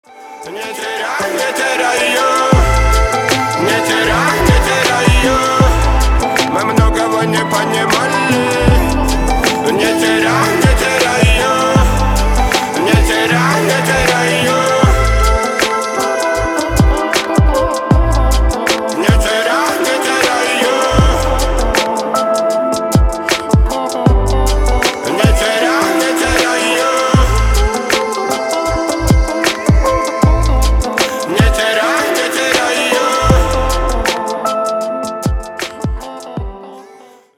Архив Рингтонов, Рэп рингтоны